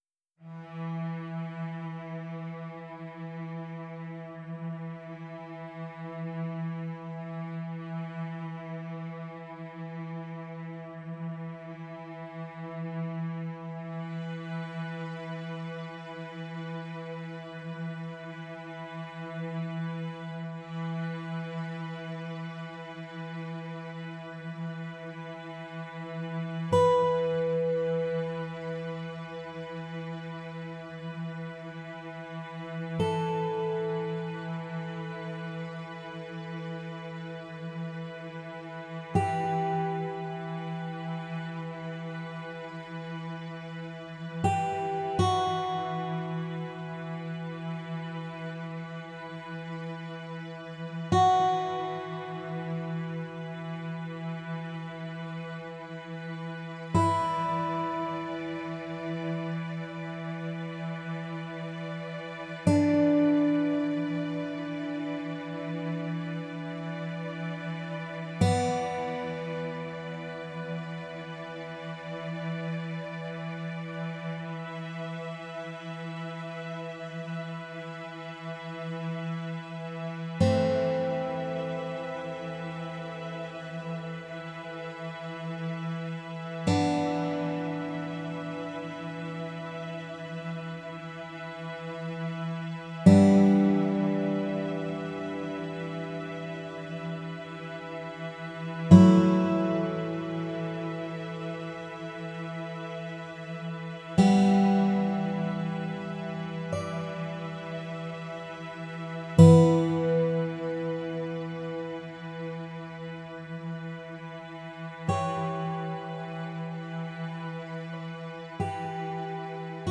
Title Moab Opus # 562 Year 2023 Duration 00:05:09 Self-Rating 4 Description More specifically, Arches National Park, Utah. mp3 download wav download Files: wav mp3 Tags: Strings, Guitar Plays: 174 Likes: 34